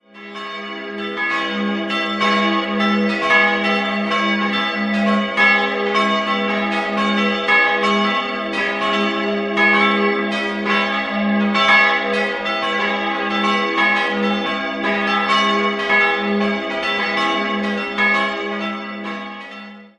Die Glocken 1 und 3 wurden 1951 von Benjamin Grüninger in Villingen gegossen, die zweitgrößte Glocke stammt aus der Zwischenkriegszeit und entstand 1919 bei Hamm in Augsburg. Im Jahr 1993 konnte mit der kleinsten Glocke aus der Gießerei Metz in Karlsruhe das Geläut vervollständigt werden.